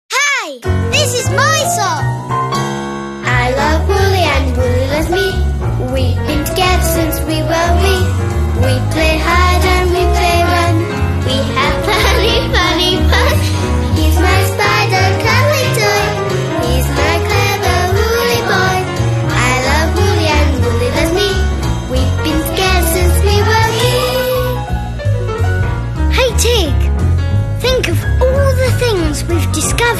Woolly & Tig Talking Spider sound effects free download